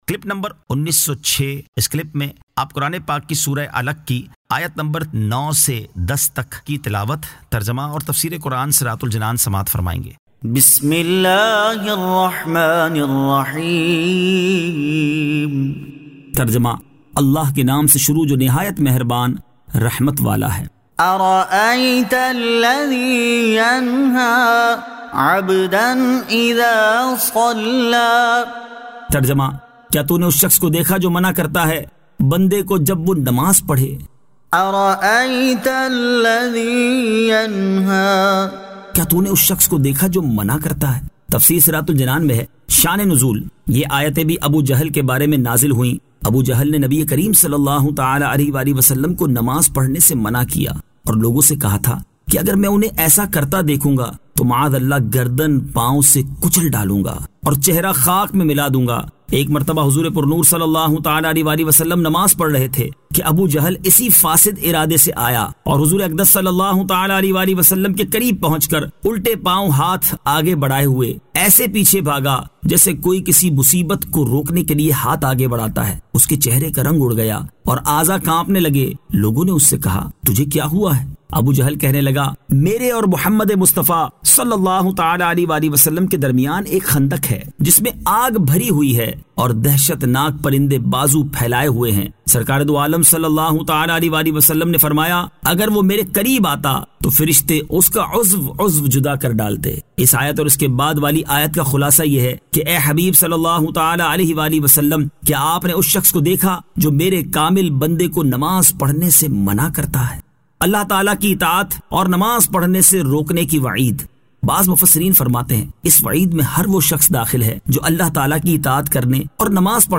Surah Al-Alaq 09 To 10 Tilawat , Tarjama , Tafseer
2025 MP3 MP4 MP4 Share سُوَّرۃُ الْعَلَقْ آیت 09 تا 10 تلاوت ، ترجمہ ، تفسیر ۔